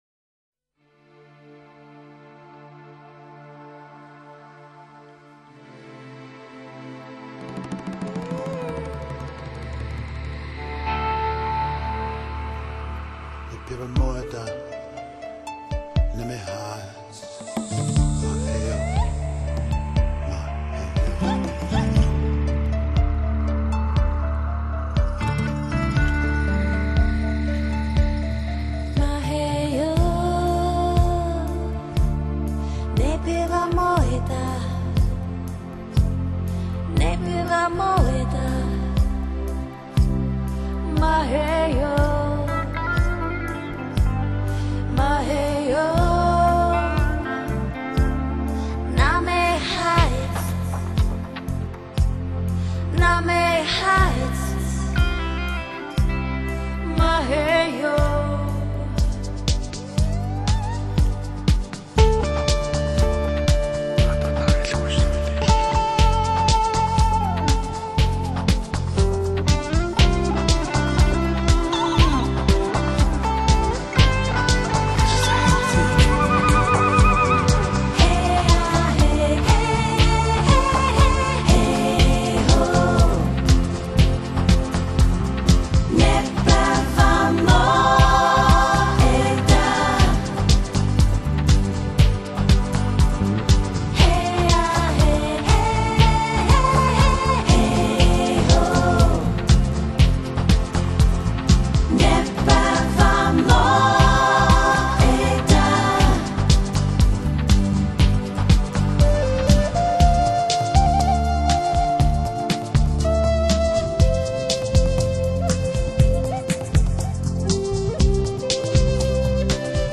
以纯净人声虔诚祝祷
一张充满生命原力的发烧人声天碟
从印地安部落民谣到蒙古双音(khoomei)唱法，中国京剧吟唱甚至是居尔特美声
游走在半音和三全音的4部以上的多部合音在专辑中随处可见。